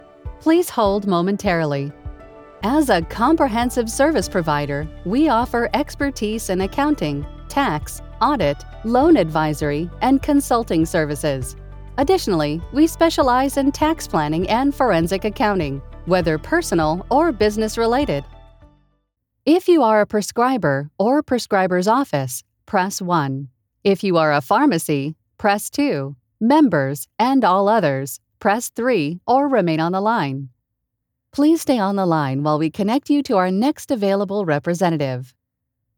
Warm, Natural and Experienced!
IVR / Phone Greetings
North American General